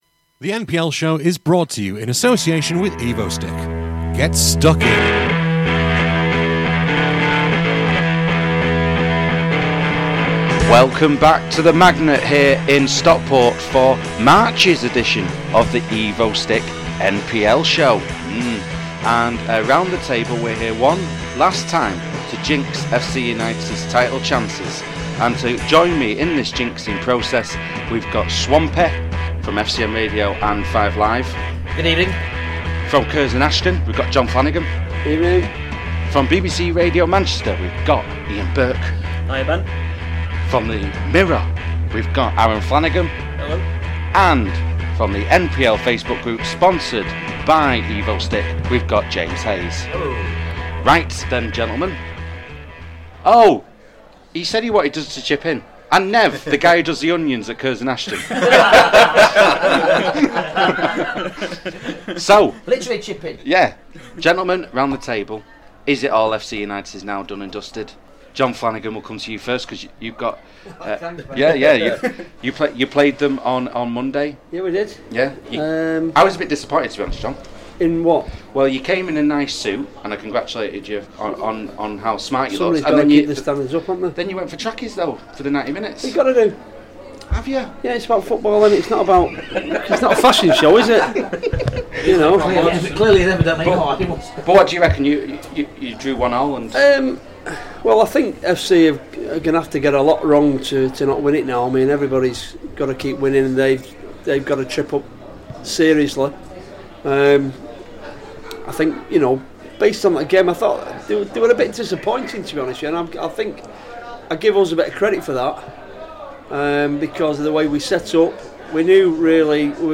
With Thanks: This programme was recorded at the Magnet in Stockport on Wednesday 8th April 2015.